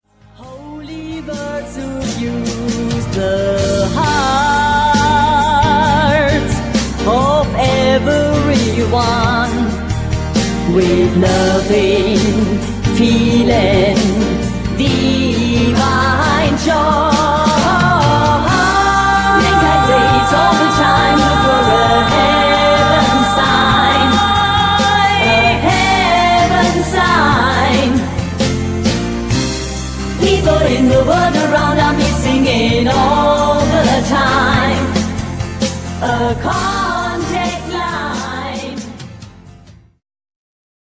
Stil: Rock-Pop
Verträumter, melodiöser Song zwischen
Rockballade und Chanson